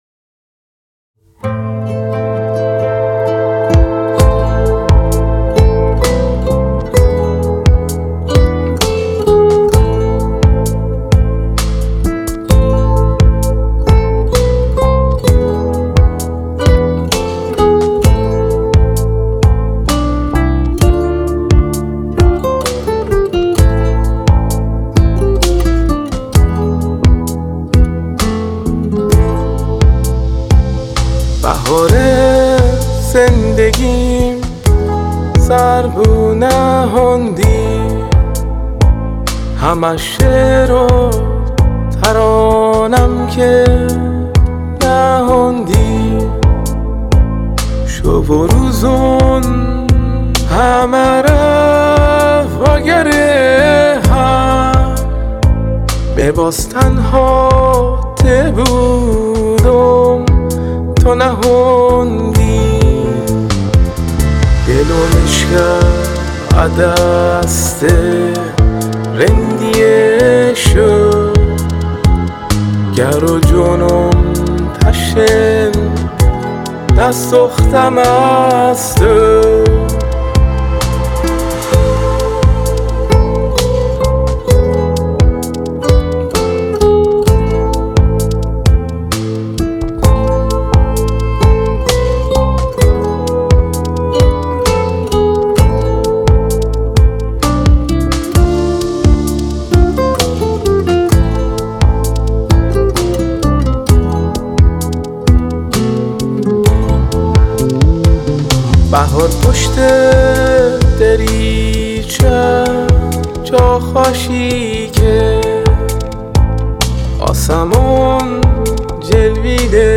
🎸 نوازنده گیتار
🪕 نوازنده گیتار باس